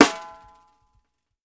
Topking Snare.wav